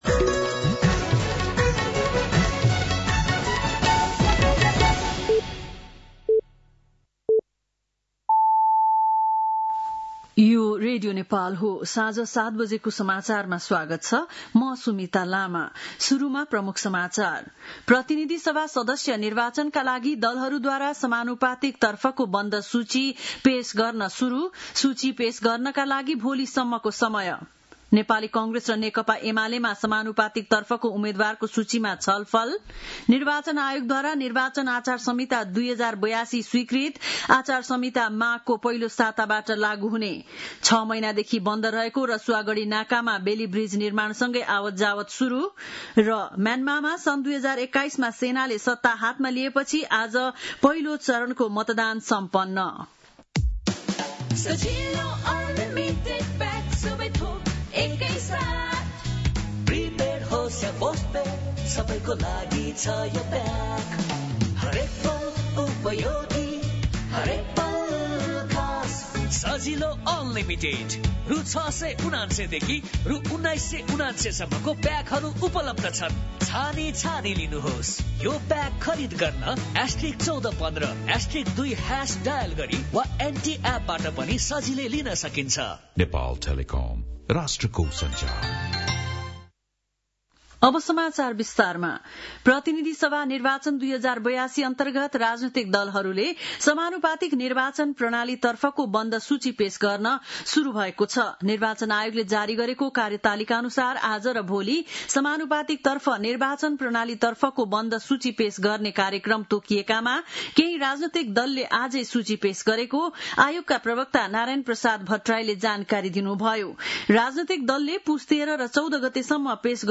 बेलुकी ७ बजेको नेपाली समाचार : १३ पुष , २०८२
7.-pm-nepali-news-1-4.mp3